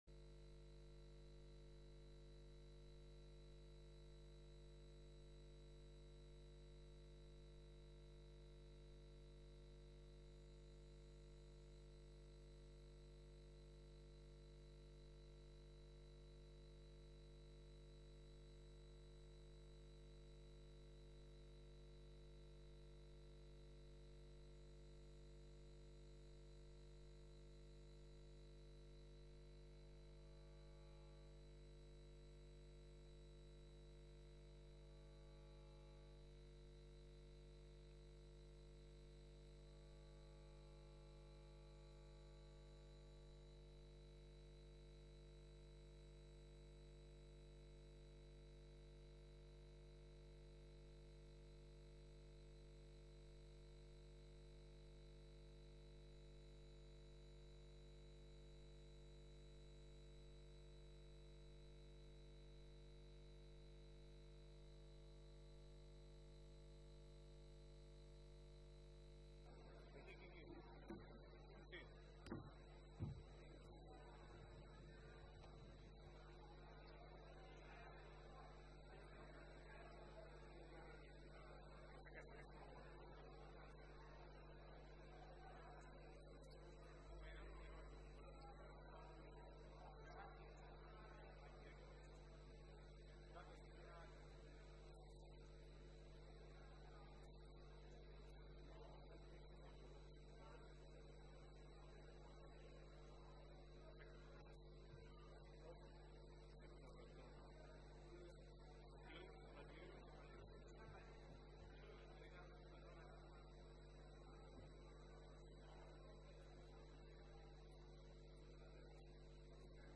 Terricabras, director honorífic de la Càtedra Ferrater Mora, pronuncia la seva lectio ultima.